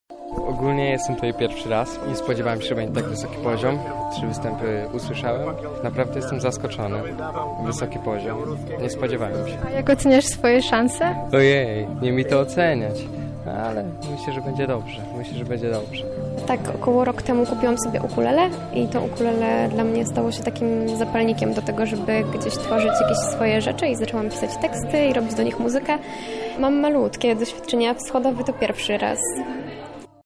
Nasi reporterzy odwiedzili sale przesłuchań i zapytali uczestników konkursu o wrażenia